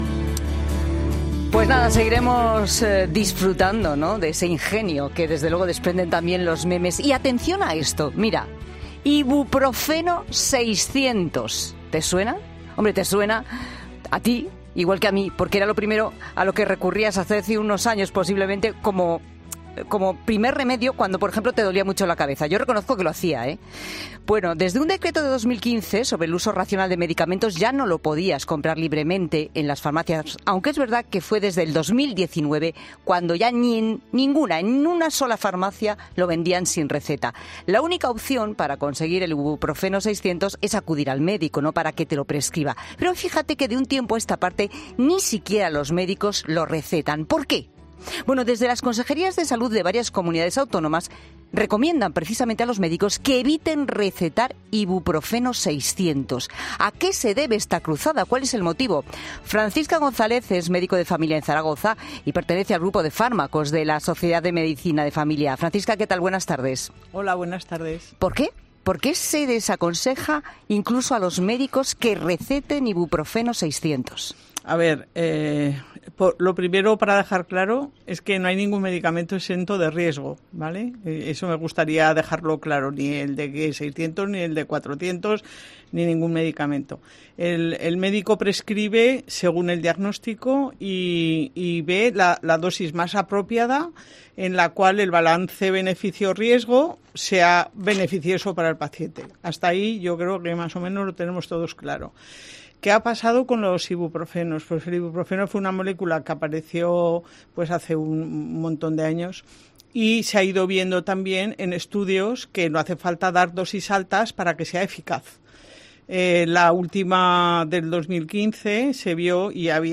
Un médico explica los riesgos que puede tener tomar el ibuprofeno sin beber agua, sin comer y con alcohol